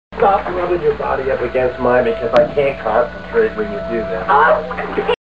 rubbing